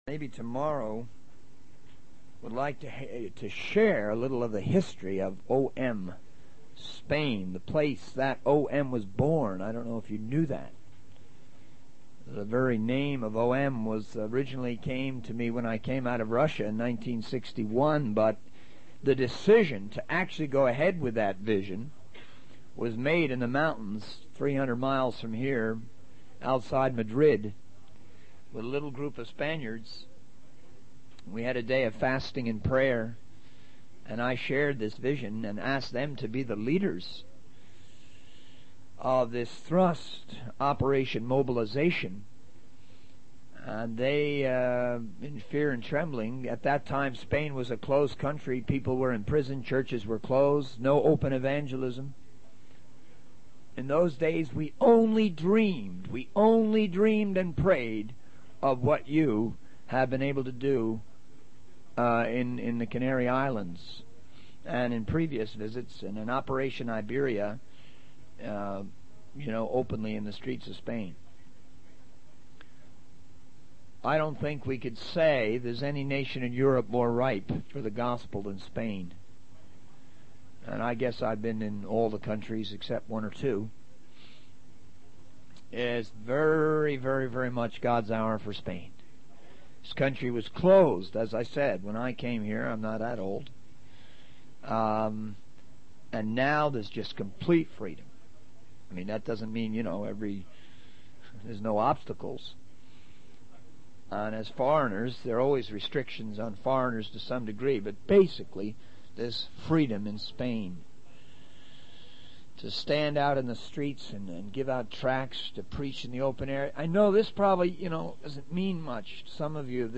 In this sermon, the speaker emphasizes the great need for missionaries in the world.